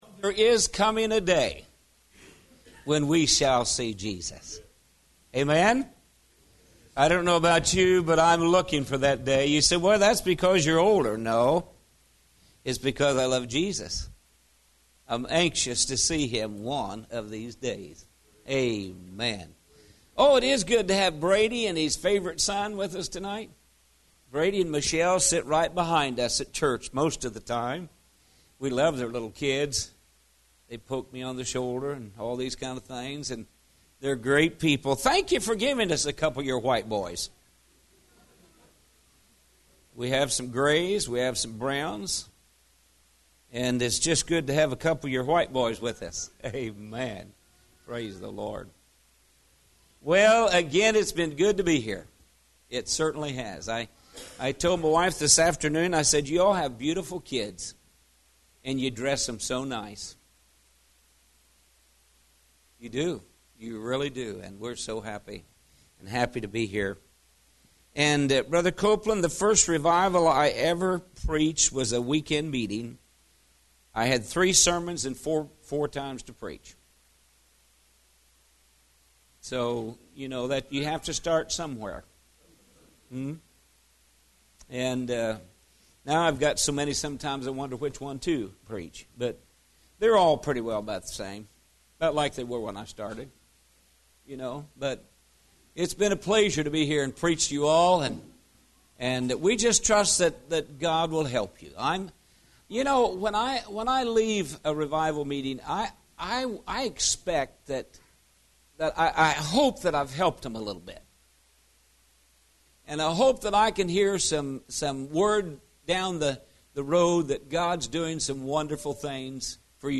Series: Spring Revival 2017